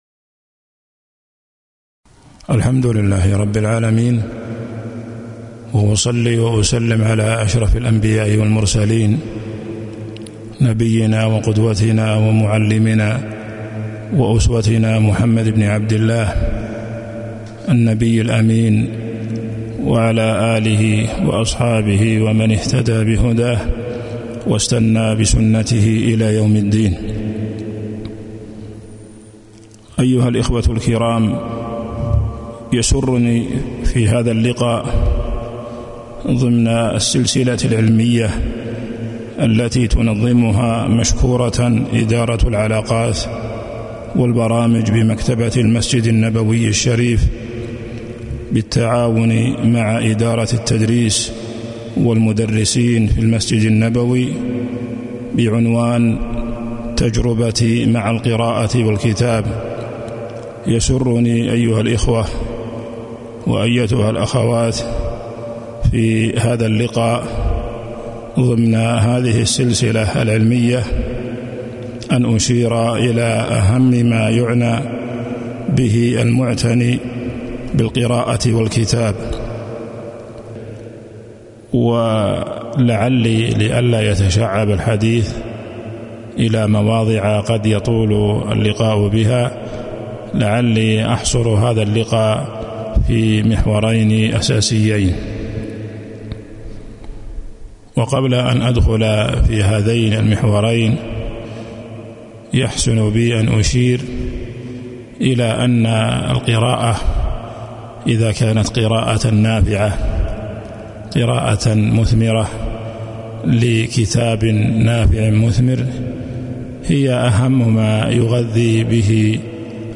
تاريخ النشر ٢٨ شوال ١٤٤٢ هـ المكان: المسجد النبوي الشيخ